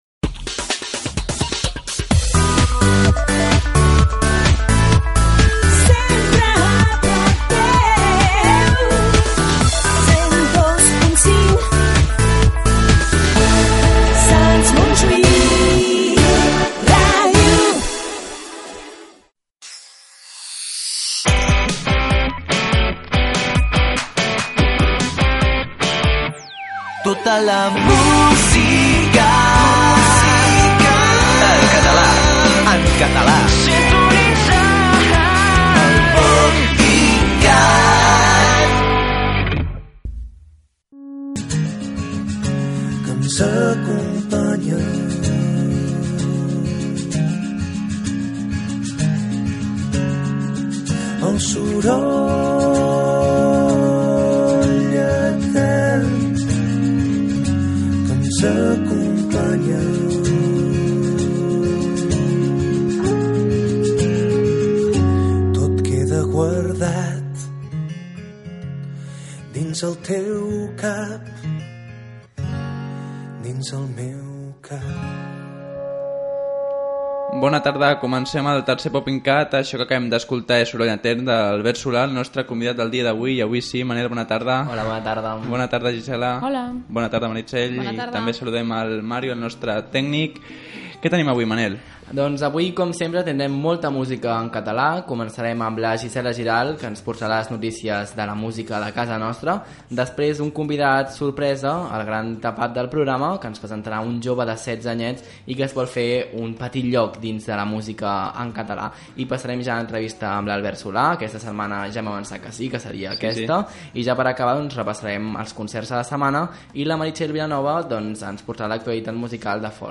Indicatiu de l'emissora, careta del programa, tema musical, presentació, tema musical, notícies musicals
Musical
FM